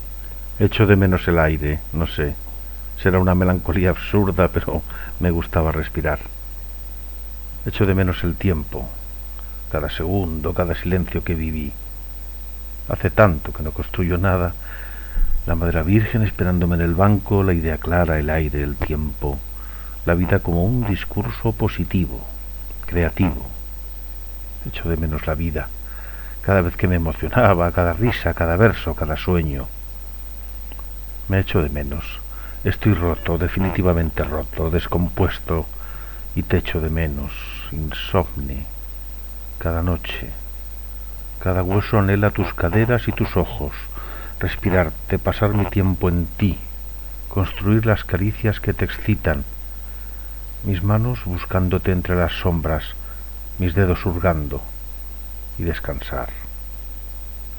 Lectura del poema otra vida